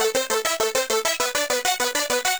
Index of /musicradar/8-bit-bonanza-samples/FM Arp Loops
CS_FMArp B_100-A.wav